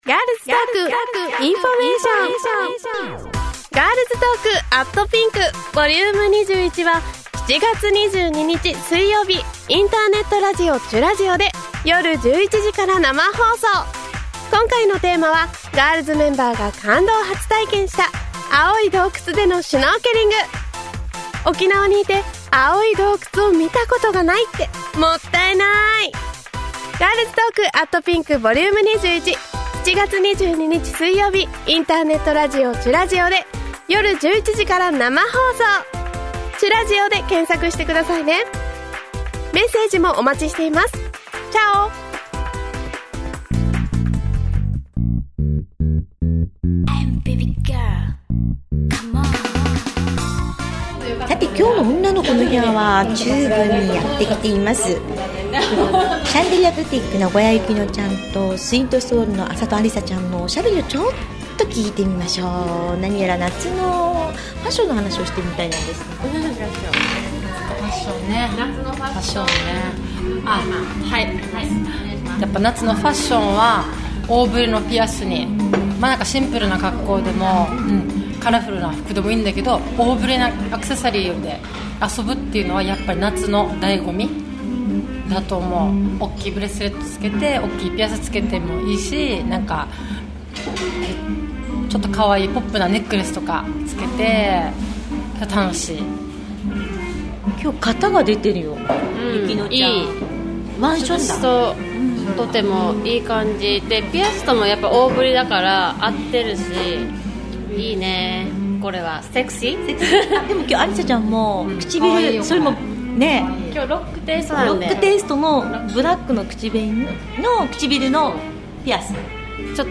ガールズ・トーク。テーマは「夏のファッション」 それではPodcast スタートです。